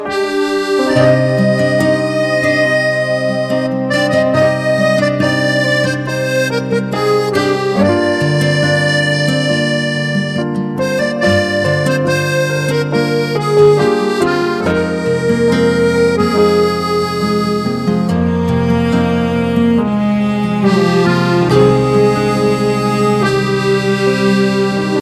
موزیک غمگین برای زنگ گوشی